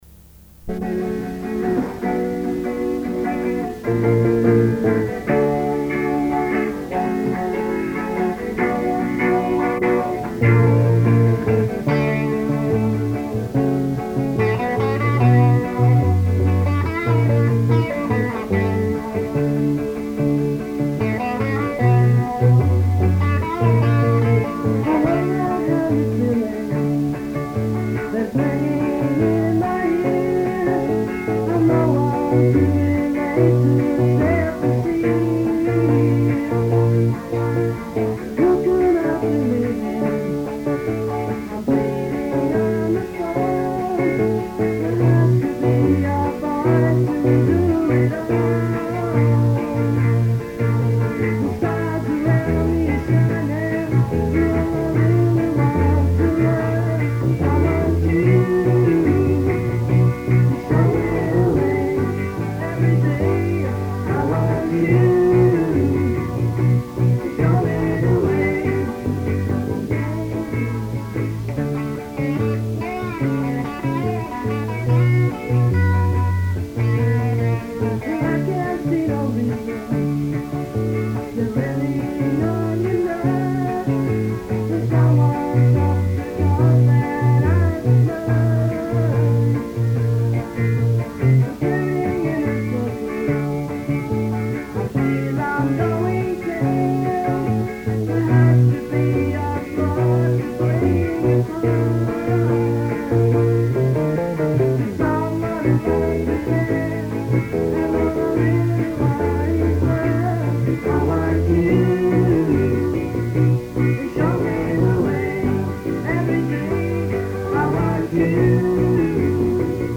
Lead Vocals and Guitar
Bass
Lead Vocals
Drums
Lead Guitar
Here are a few Orpheus covers recorded during a practice on Sunday 14th of June, 1976!: